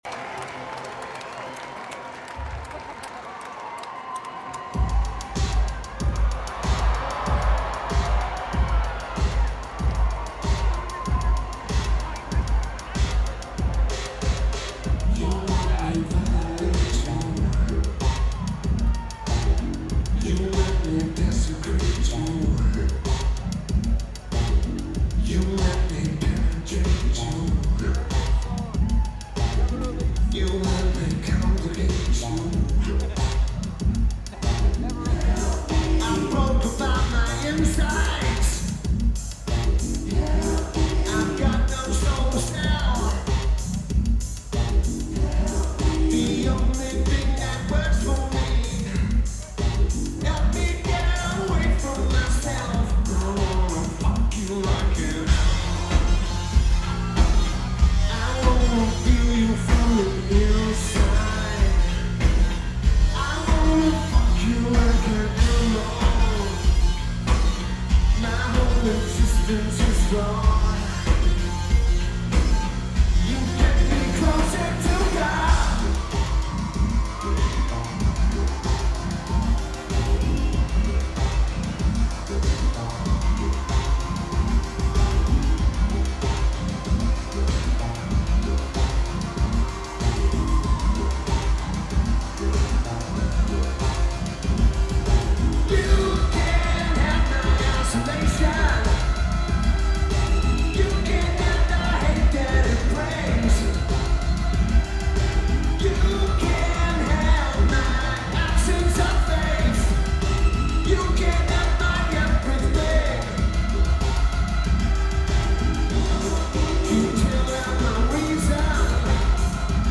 Keyboards/Bass/Backing Vocals
Drums
Guitar
Lineage: Audio - AUD (Sony PCM-A10)